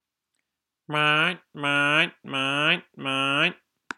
seagle.mp3